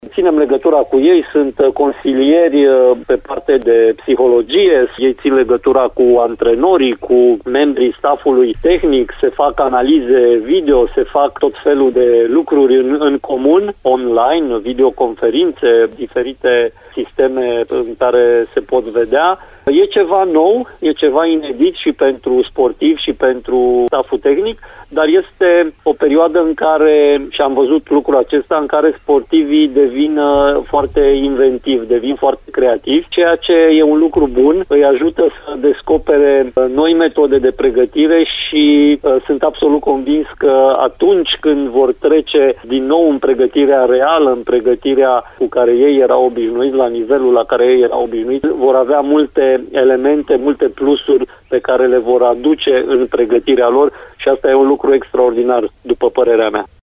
Afirmația a fost făcută într-un interviu pentru Radio Timișoara, de președintele Comitetului Olimpic și Sportiv Român, Mihai Covaliu: